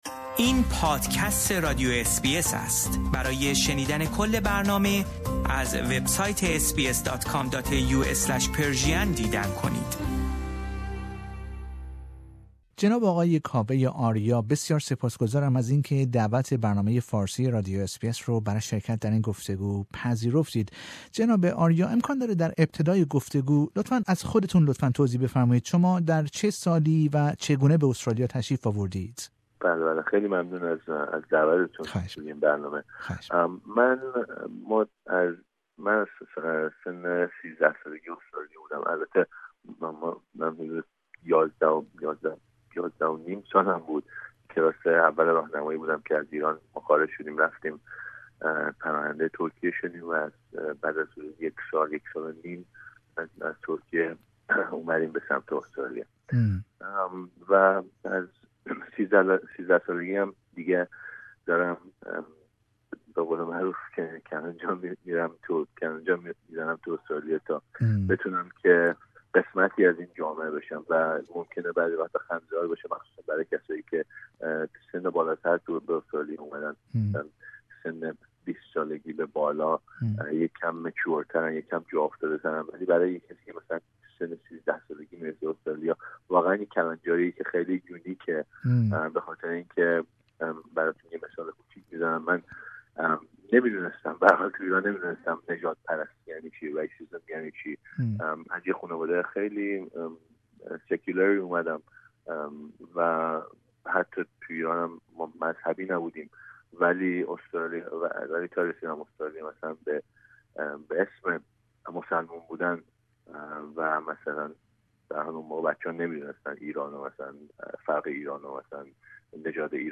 او در گفتگو با رادیو اس بی اس فارسی از خود، نحوه خروجش از ایران، مهاجرت به استرالیا، فراز و نشیب های زندگی اش و در نهایت دلیلی که خود را یک مسلمان فرهنگی می نامد، سخن می گوید.